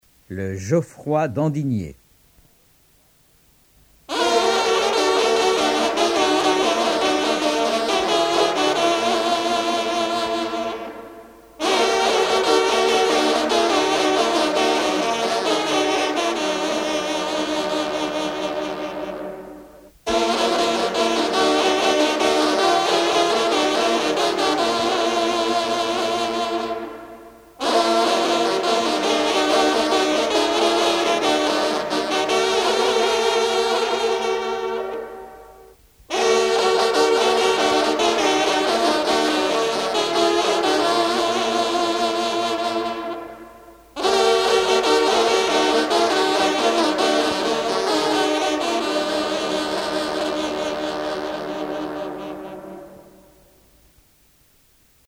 trompe - fanfare - personnalités
circonstance : vénerie